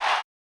overused chant.wav